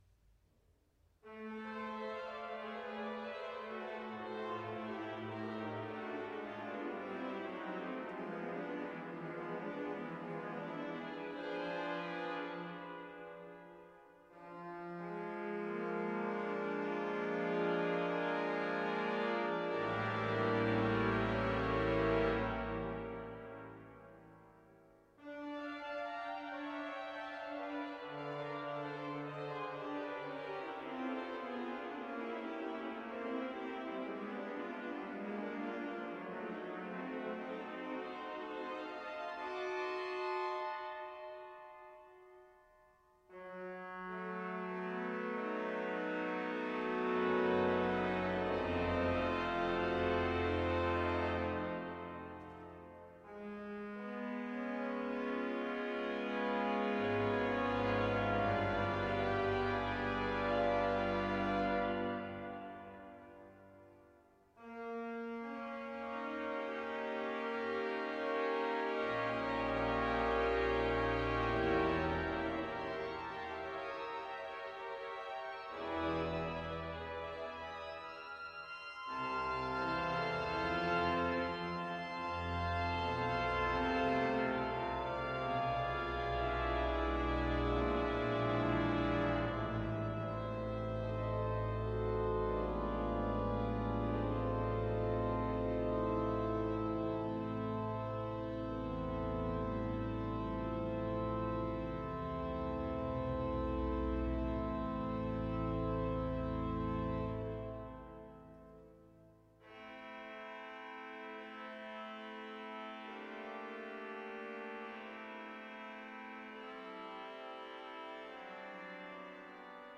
Live performances
Choral No. 3 in A minor